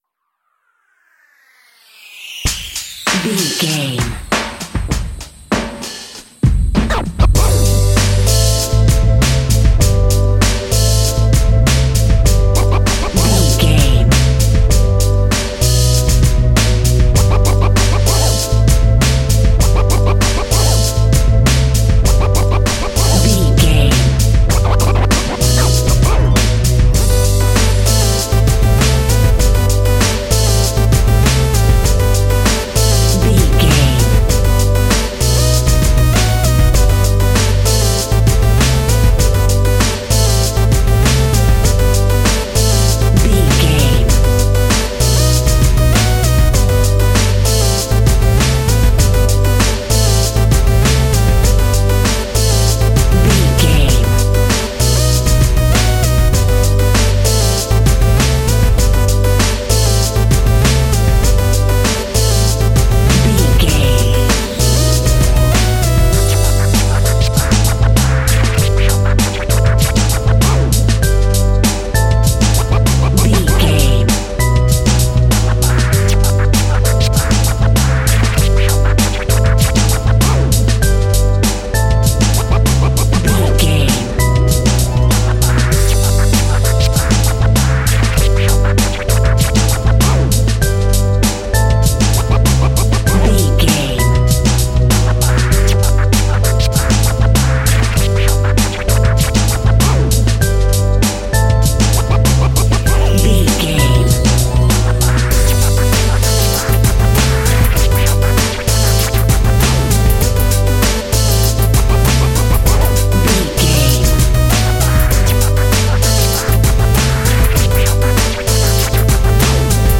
Ionian/Major
hip hop instrumentals
downtempo
synth lead
synth bass
synth drums
turntables